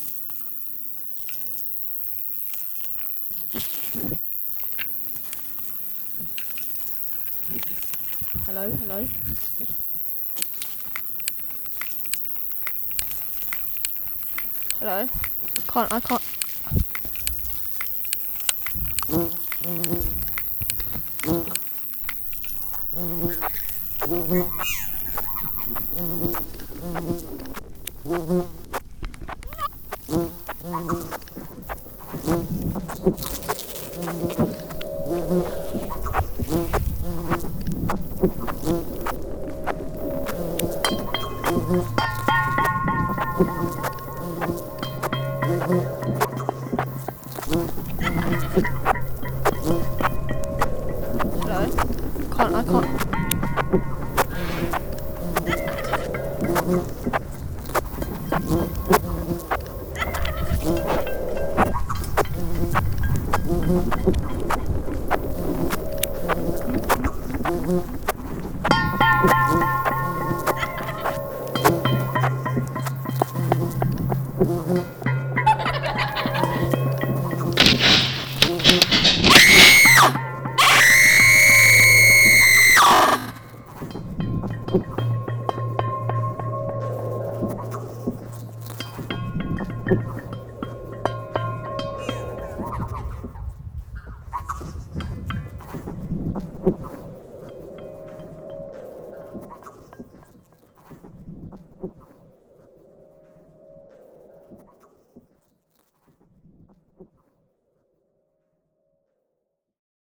- Creating a collaborative soundtrack using field recordings from across the site
Click here to listen to the audio work which the young people produced during the Summer School. Content warning: The audio contains high pitched tones and sounds of flies buzzing, with loud screams in the second half.